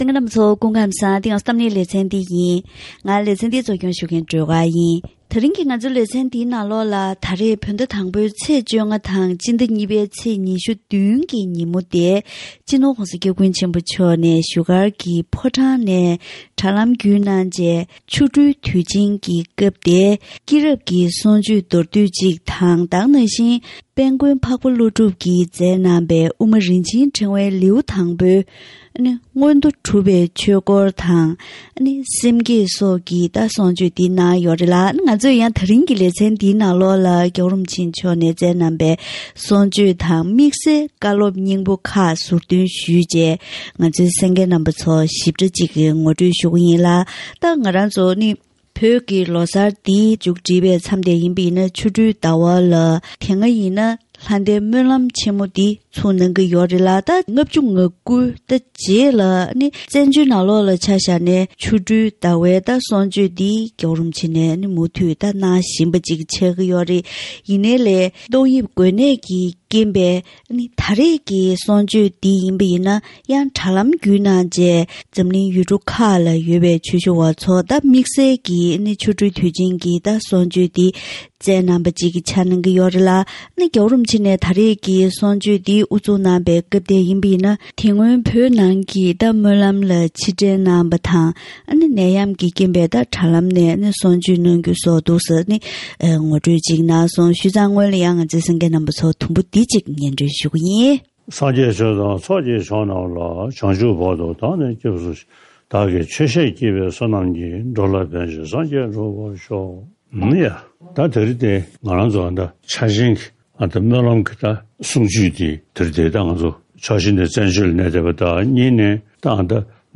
ཆོ་འཕྲུལ་དུས་ཆེན་ཉིན་གསུང་ཆོས་གནང་སྐབས།